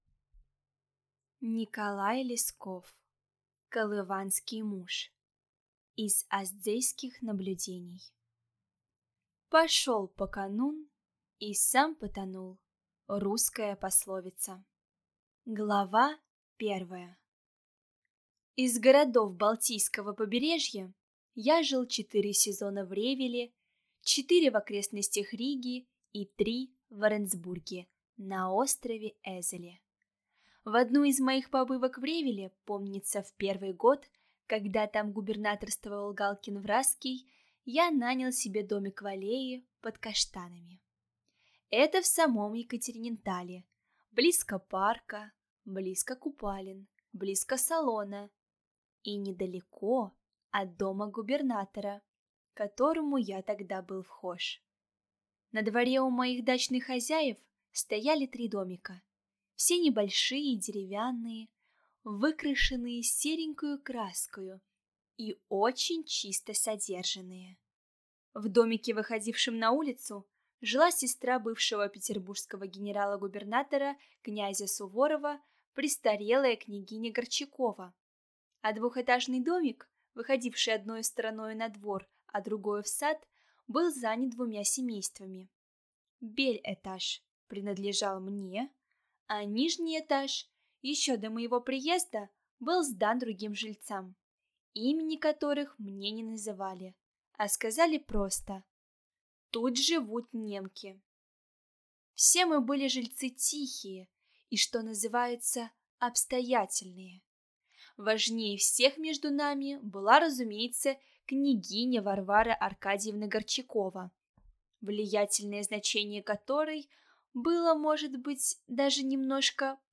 Аудиокнига Колыванский муж | Библиотека аудиокниг